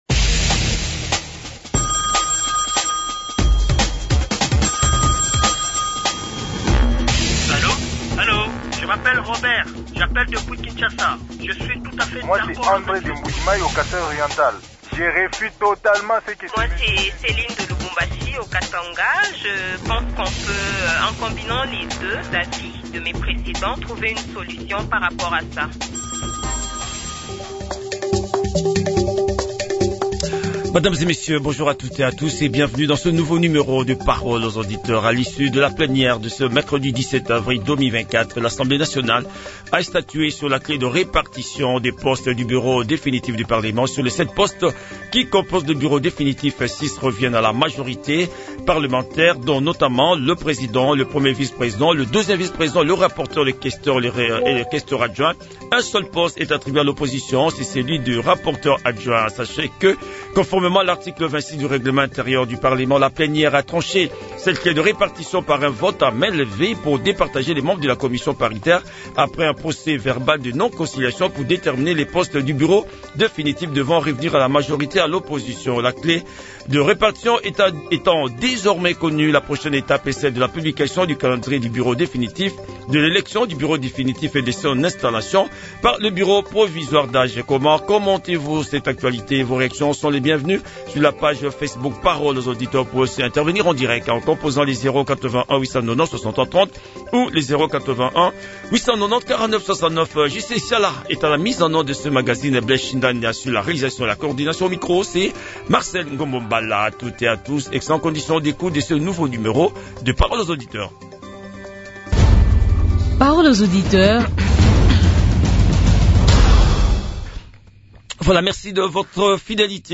Gary Sakata, professeur d’université et député national est l’invité du magazine Parole aux auditeurs